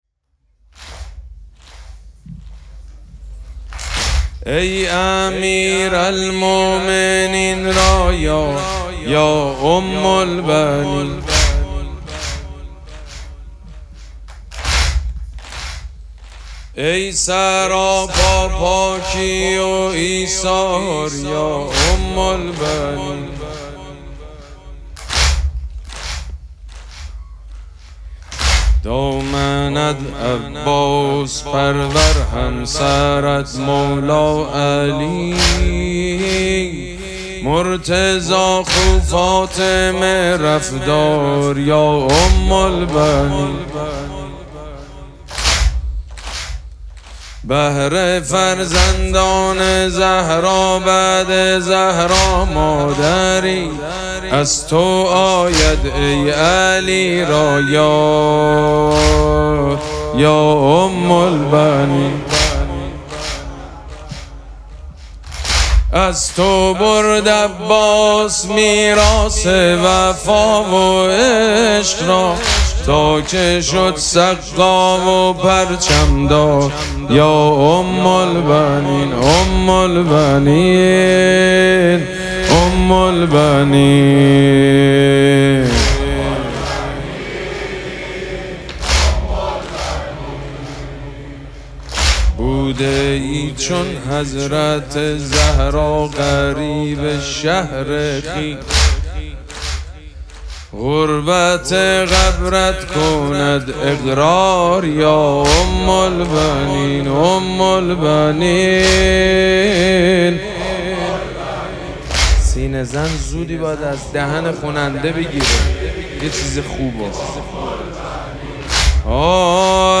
مراسم عزاداری وفات حضرت ام‌البنین سلام‌الله‌علیها
حسینیه ریحانه الحسین سلام الله علیها
حاج سید مجید بنی فاطمه